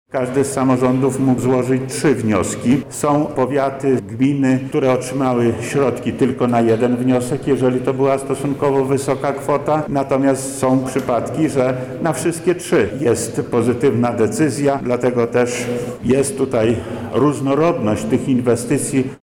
Jest to realizacja tych inwestycji, na które samorządów nie było stać – mówi wojewoda lubelski Lech Sprawka: